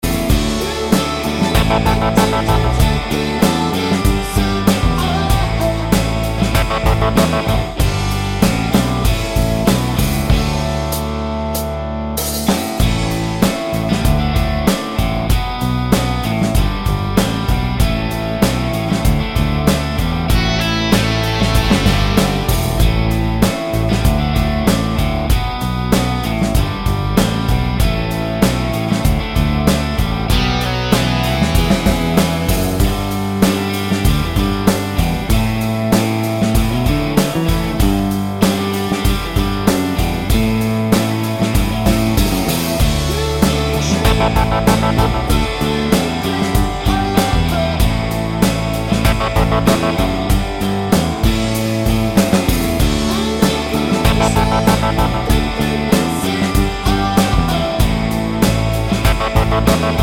Clean Backing Vocals Indie / Alternative 3:55 Buy £1.50